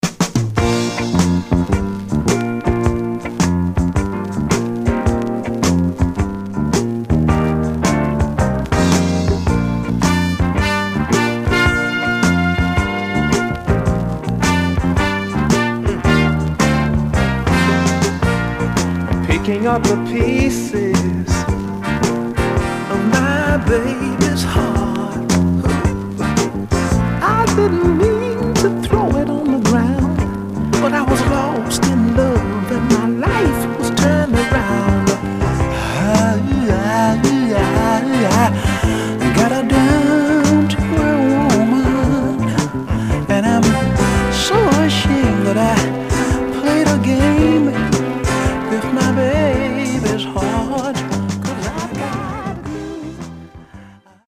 Some surface noise/wear
Mono
Soul